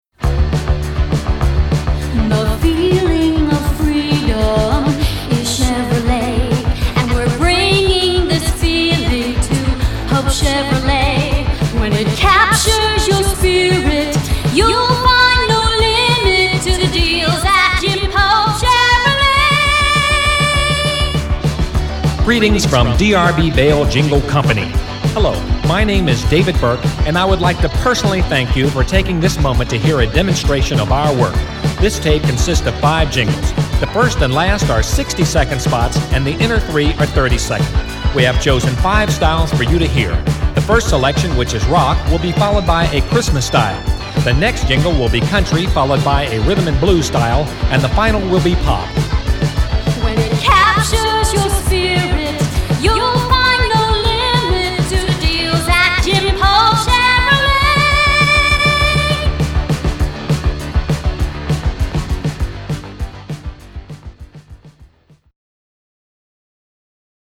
Jingles!